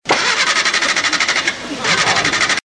ENGINE SPUTTERING AND CRANKING.mp3
A broken, old motor, trying to sputter his way out.
engine_sputtering_and_cranking_gtu.ogg